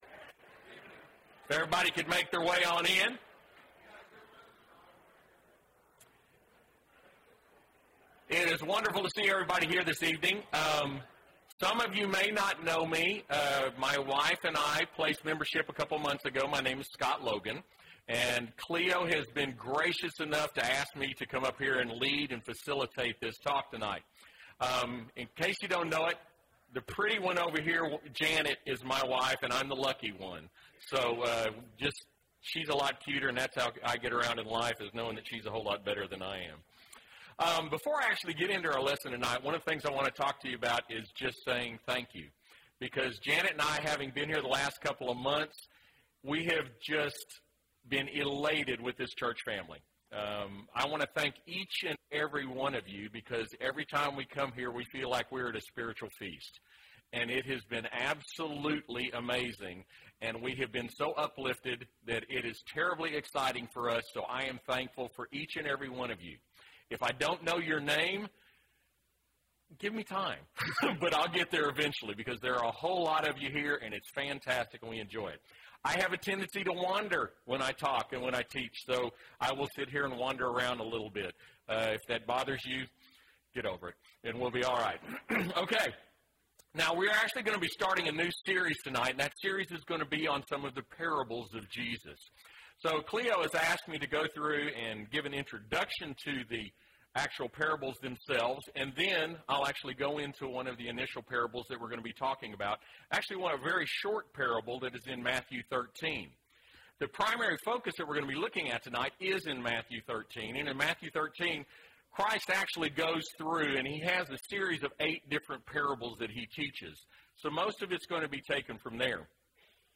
A Study of Selected Parables (1 of 7) – Bible Lesson Recording
Wednesday PM Bible Class